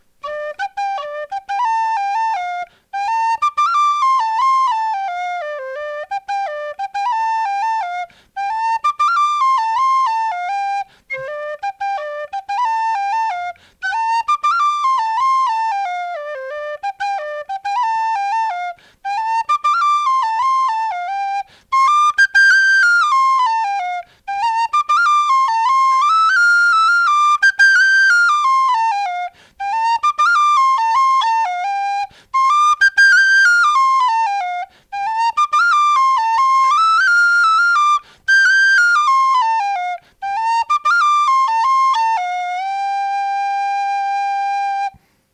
Ma première gigue à la flûte à dix sous (Clarke Sweetone en Do) !